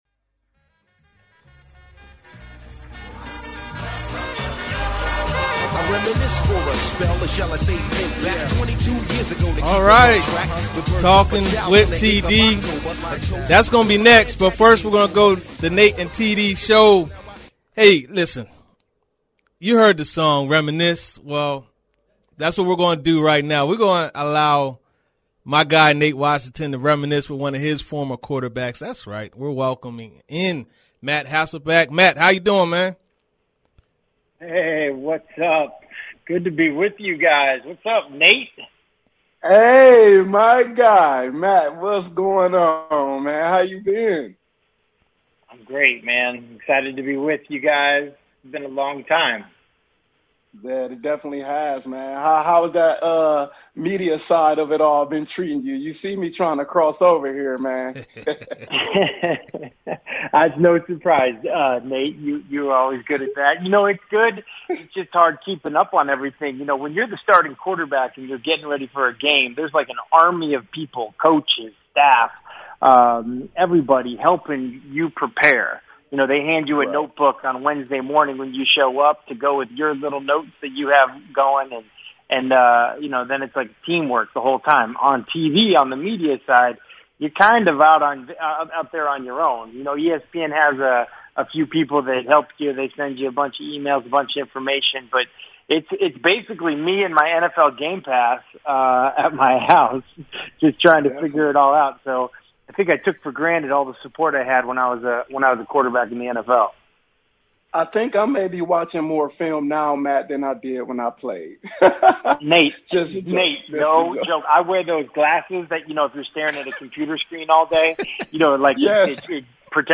Matt Hasselbeck Interview